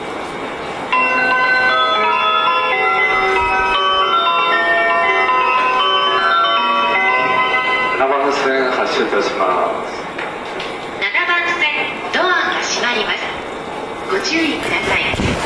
発車メロディをかき消してくれます。
ドアが開いた瞬間に発車メロディが鳴り出すのもこの駅ならではです。